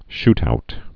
(shtout)